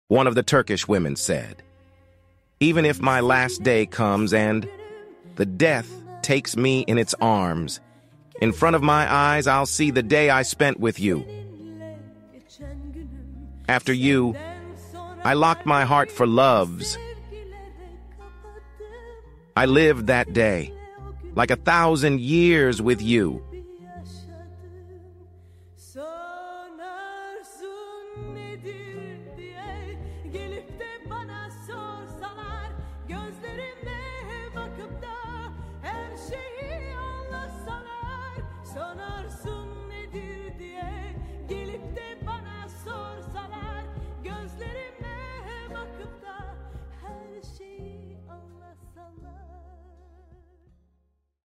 Turkish pop song